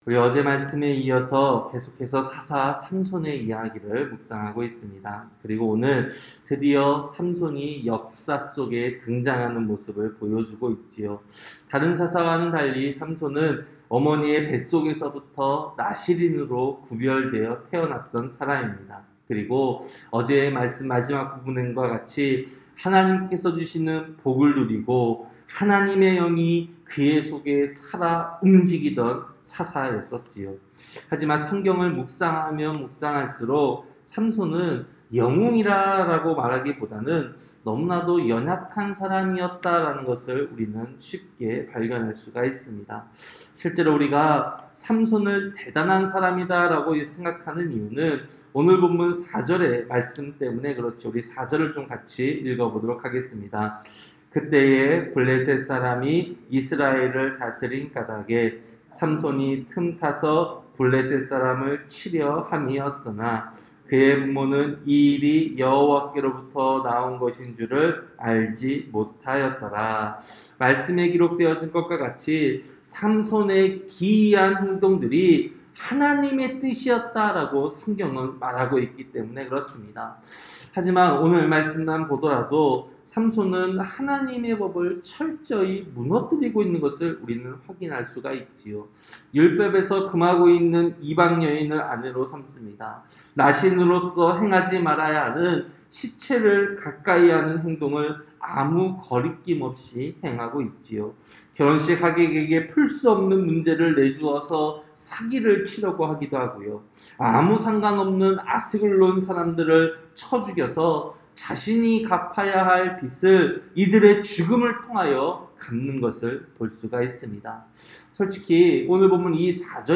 새벽설교-사사기 14장